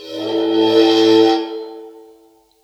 susCymb1-bow-2.wav